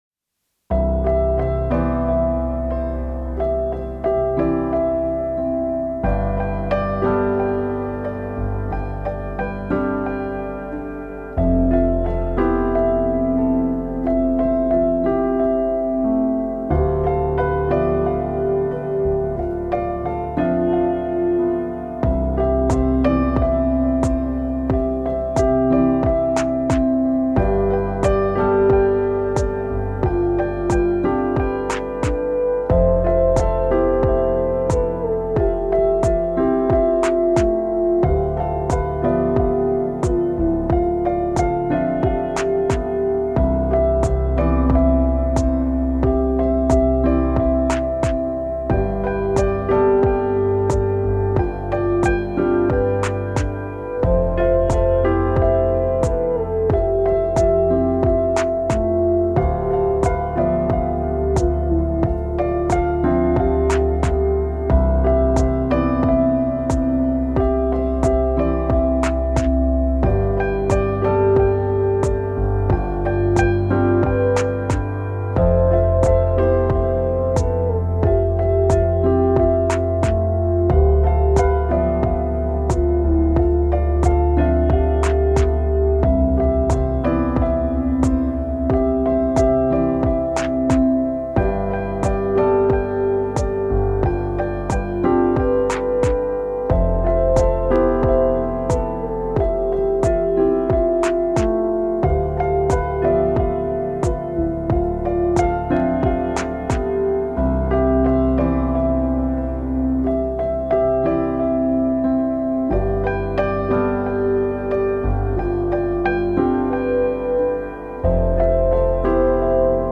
夢の中に漂うような浮遊感のあるアンビエント系BGM
アンビエント 2:17